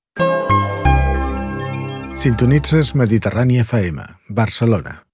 Tres indicatius de la ràdio